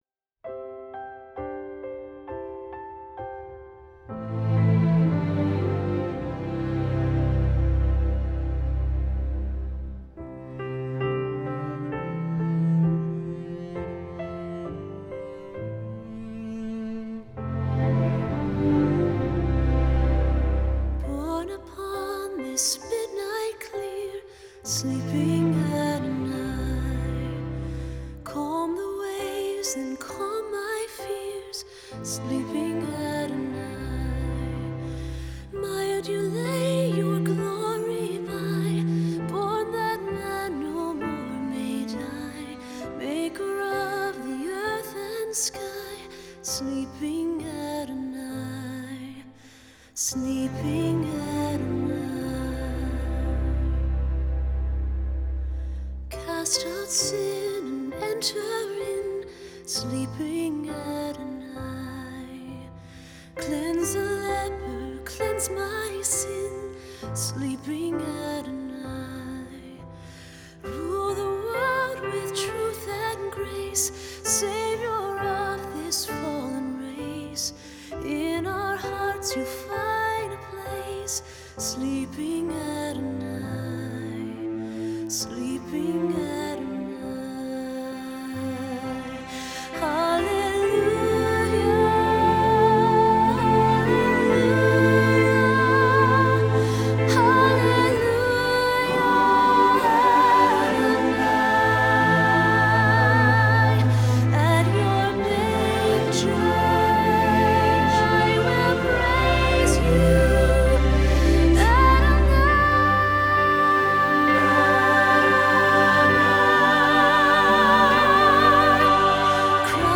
Choral Christmas/Hanukkah
SATB